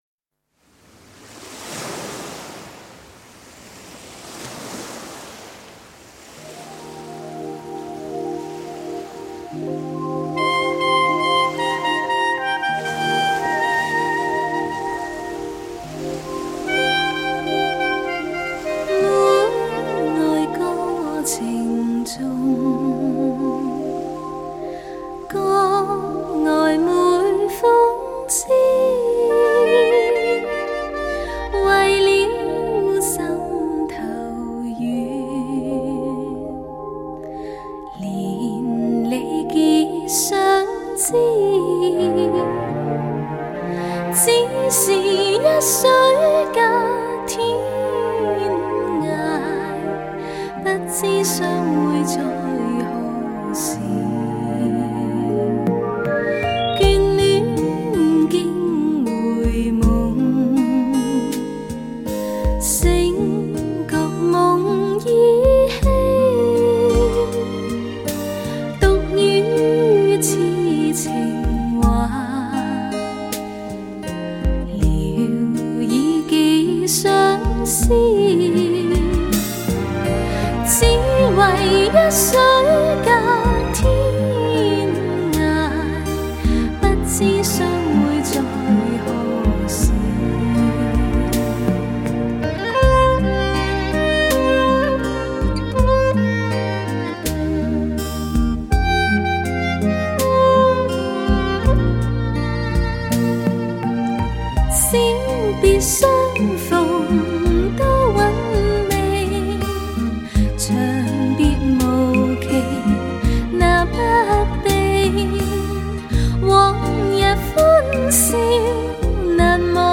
清新温柔的歌声
超级模拟A2HD3后期母带处理技术
与超级压片HQCD制式完美结合